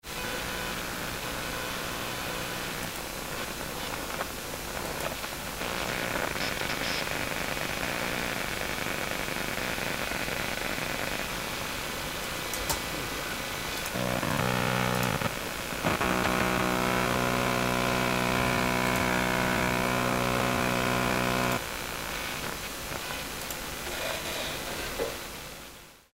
RFI/EMI Geräusche (zirpen, fiepen) trotz externe USB-Karte | ComputerBase Forum
(kurzes Geklicke im Browser, dann YT-Video, dann Computerspiel).
Ich will den Thread jetzt hier aber auch nicht hijacken, aber ich dachte das könnte helfen das Gefiepe auch mal gehört zu haben ;) Klingt jedenfalls, als wäre es dasselbe Störgeräusch wie beim Ersteller.
Volllast hörst Du bei meinem verlinkten Soundfile im letzten Drittel, da läuft Arma2 mit ca. 60fps und für jedes Frame scheint ein Rauschsignal generiert zu werden. Ähnlich bei Videos (zweites Drittel), da nicht ganz so laut (vielleicht weil's nur 30fps sind?).
stoergeraeusche.mp3